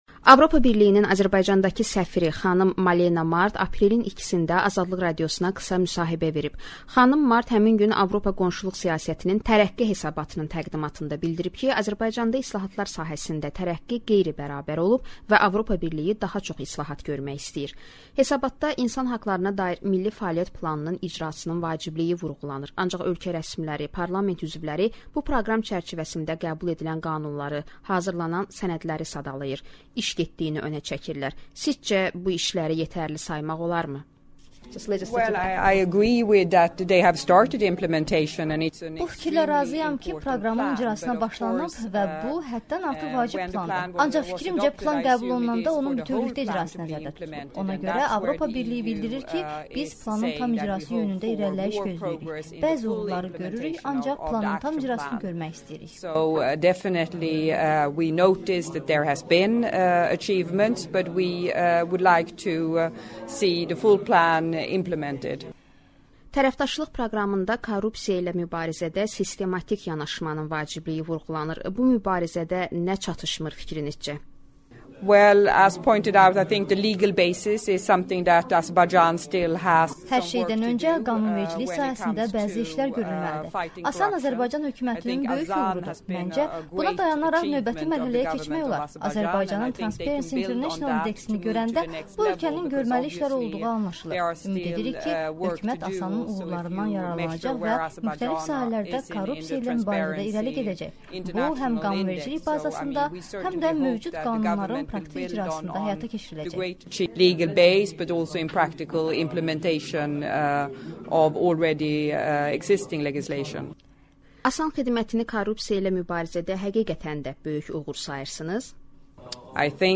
AB-nin səfəri Malena Mard ilə müsahibə